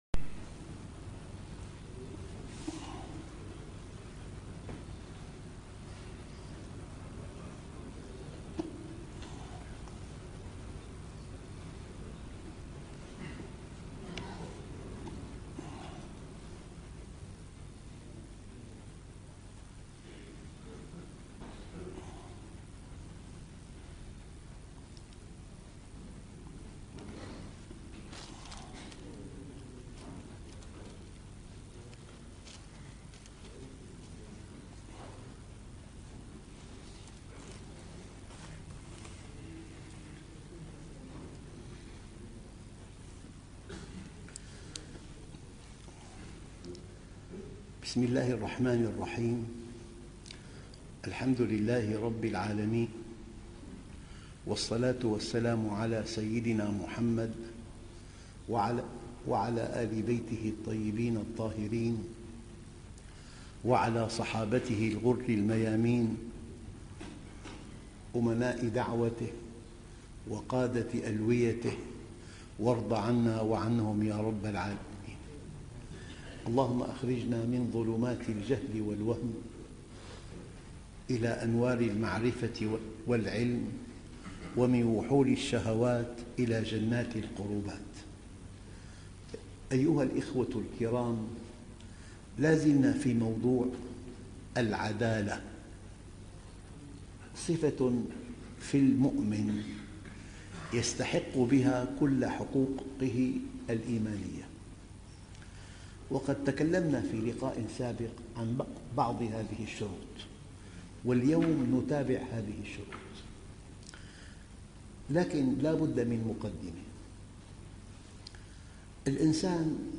العدالة من صفات المؤمن...( 1/12/2017) دروس مسجد التقوى -عمان- الأردن - الشيخ محمد راتب النابلسي